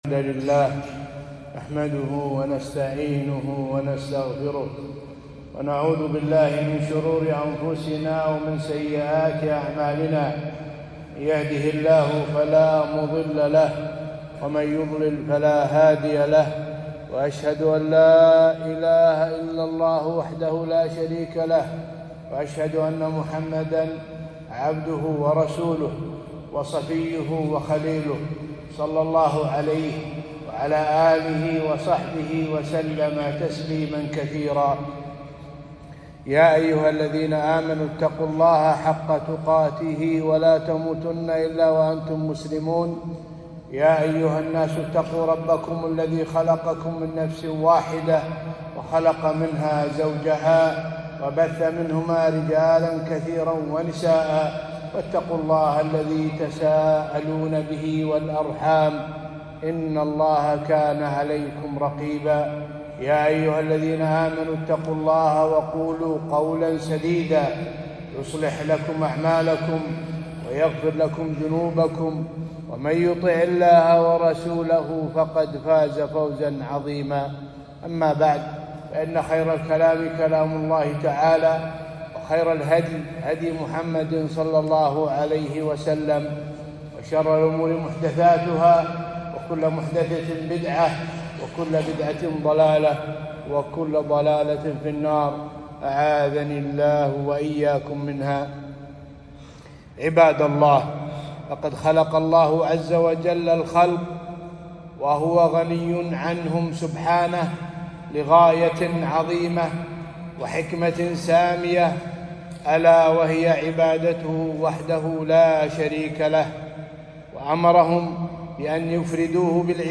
خطبة - التوحيد حق الله على العباد